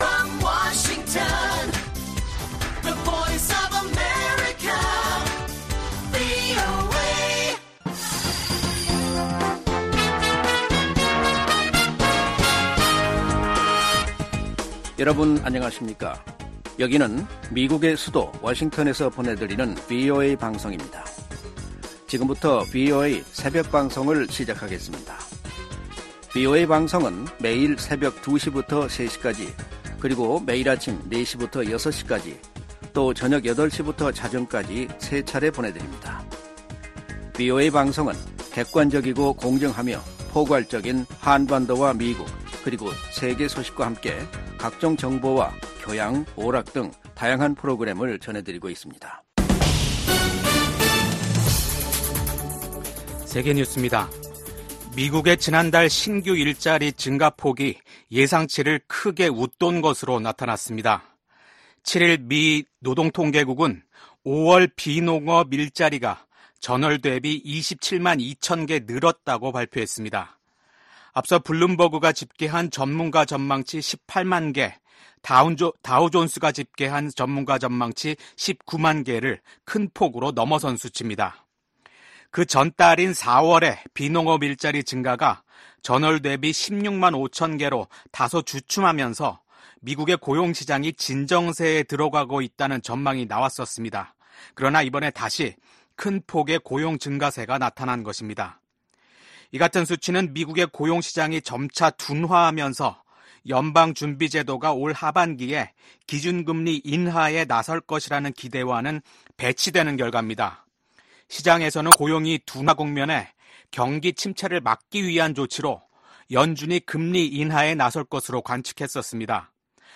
VOA 한국어 '출발 뉴스 쇼', 2024년 6월 8일 방송입니다. 미국, 한국, 일본 3국의 협력은 인도태평양의 안보 구조와 정치 구조를 위한 ‘근본적인 체제’라고 미 국가안보부보좌관이 평가했습니다. 블라디미르 푸틴 러시아 대통령은 최근 세계 주요 뉴스통신사들과의 인터뷰에서 한국이 우크라이나에 무기를 공급하지 않고 있다고 이례적으로 감사 표시를 했습니다.